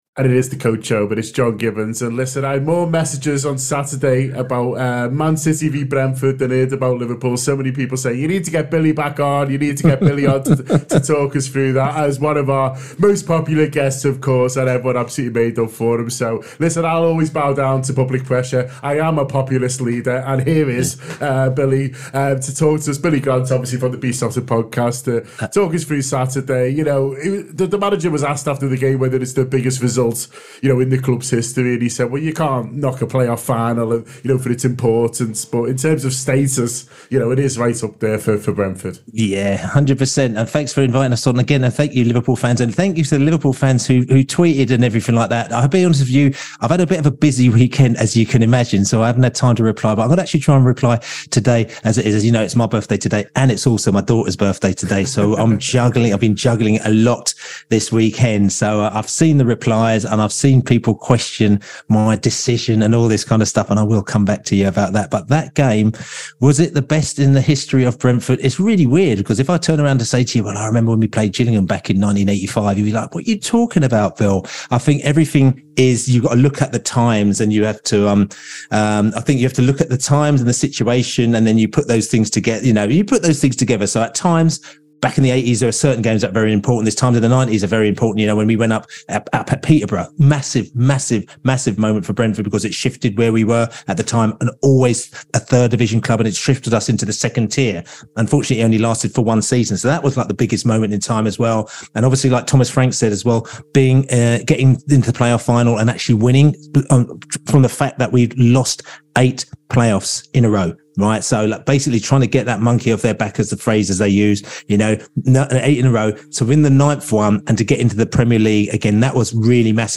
Below is a clip from the show – subscribe for more on Brentford’s best result…